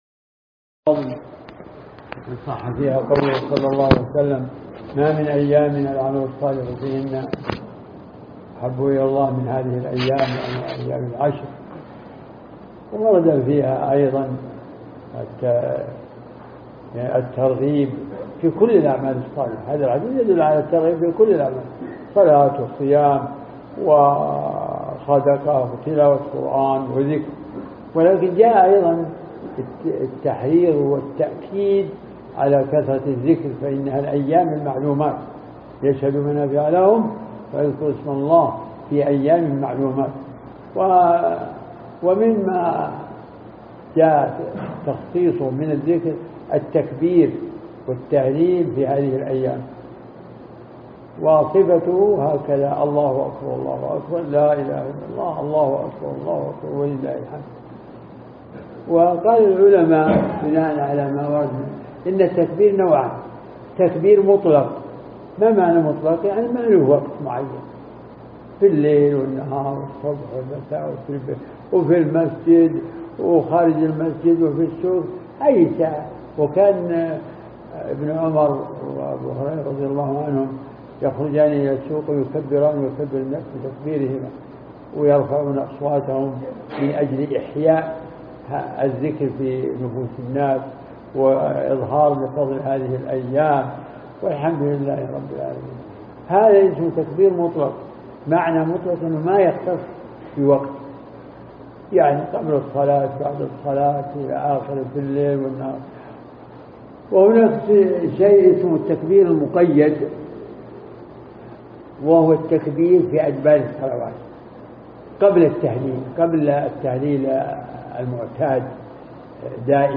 عنوان المادة كلمة عن فضل العشر الأوائل من ذي الحجة